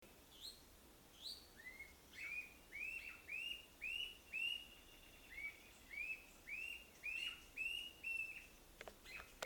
Atila Encapuchado (Attila rufus)
Nombre en inglés: Grey-hooded Attila
Localidad o área protegida: Pe da Serra do Tabuleiro--estrada Pilões
Condición: Silvestre
Certeza: Fotografiada, Vocalización Grabada